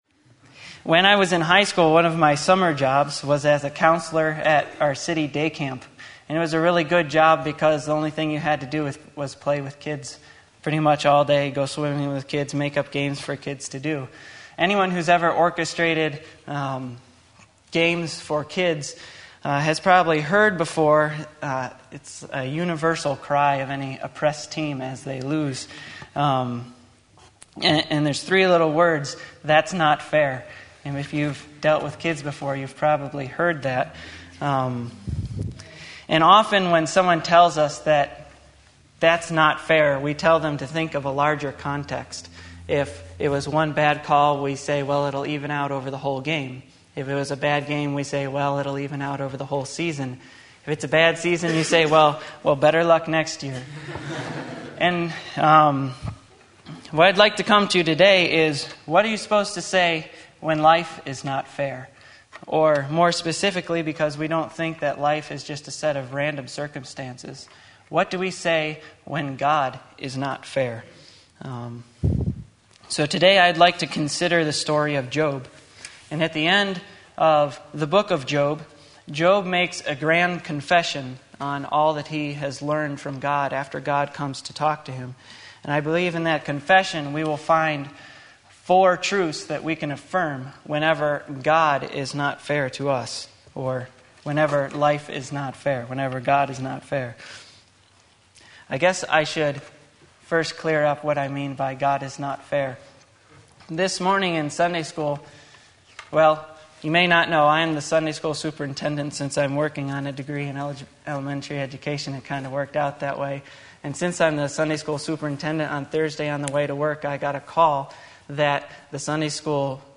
Sermon Link
When God Is Not Fair Job 42:1-6 Sunday Afternoon Service